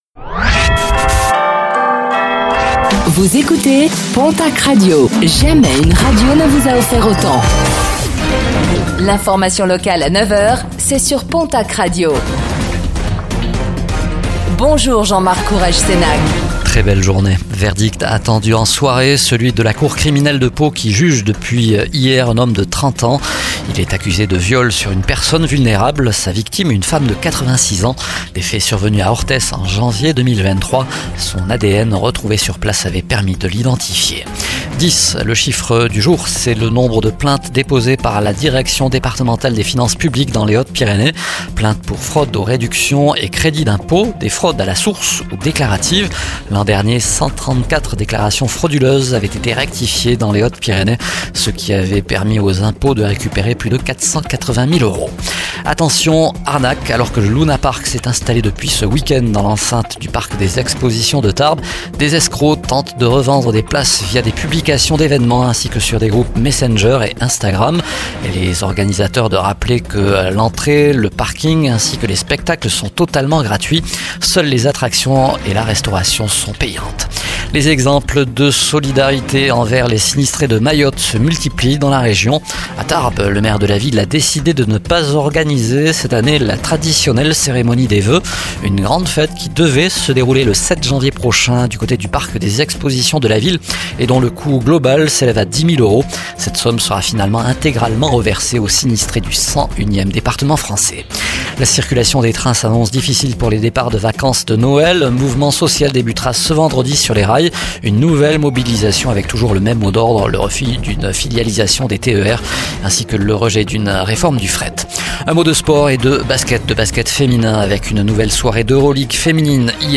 Réécoutez le flash d'information locale de ce mercredi 18 décembre 2024